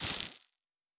Sci-Fi Sounds / Electric / Spark 13.wav
Spark 13.wav